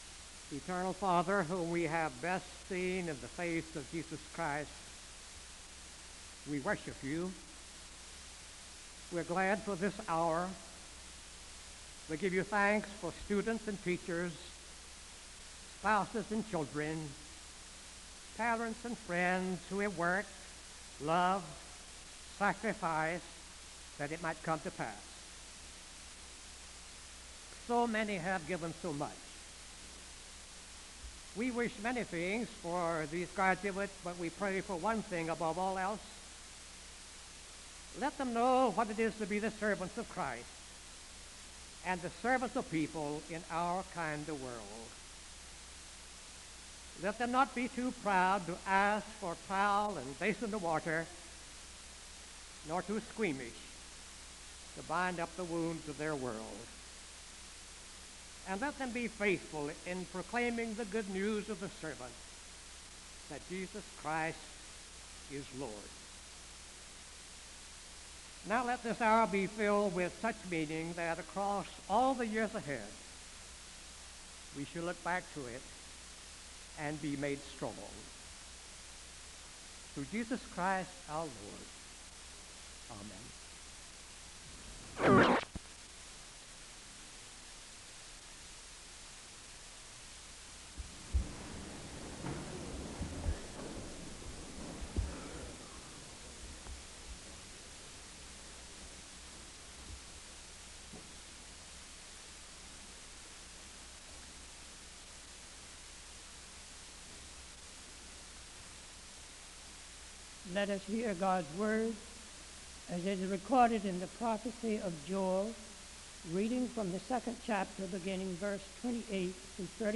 Audio quality is poor in some parts of the recording.
The choir sings the anthem (0:33:23-0:37:50).